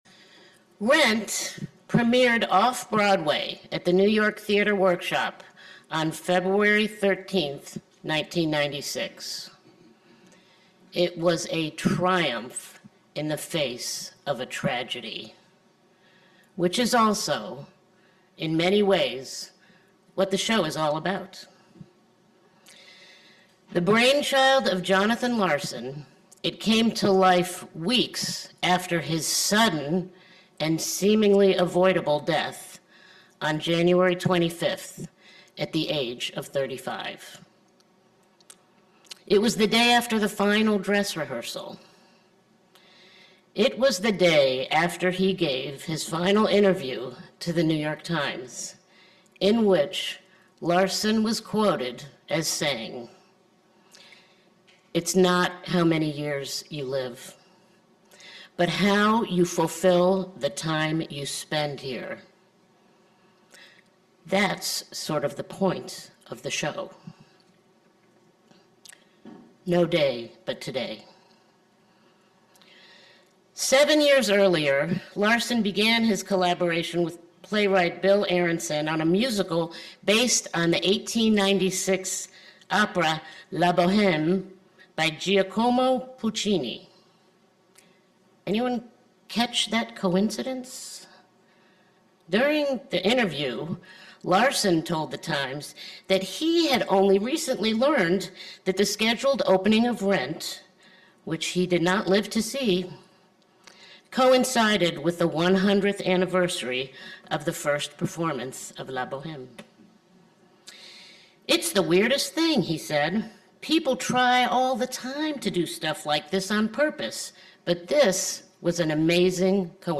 This sermon highlights the enduring legacy of the musical Rent and its creator, Jonathan Larson, who tragically died just before the show’s debut.